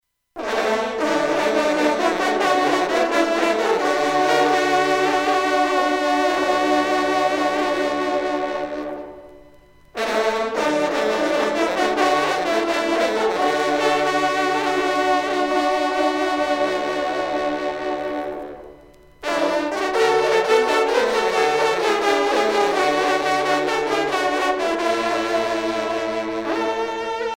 trompe - fanfare - animaux
circonstance : vénerie